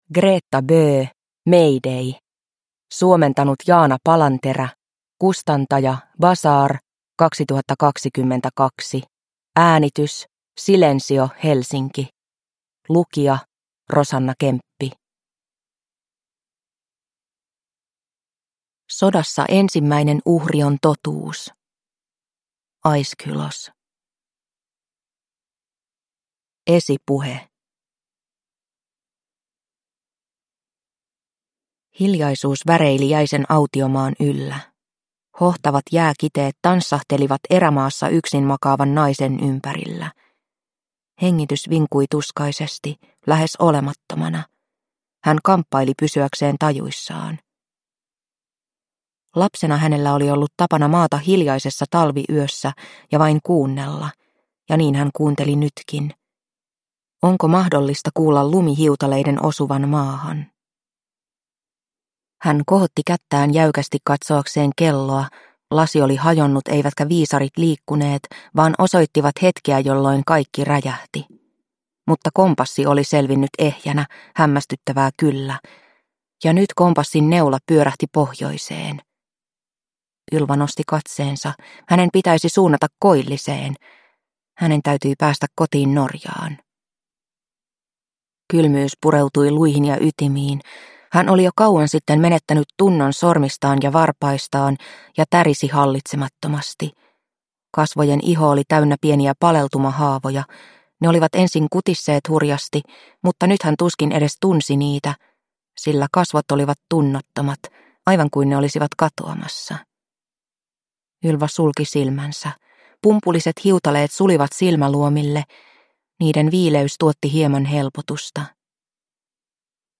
Mayday – Ljudbok – Laddas ner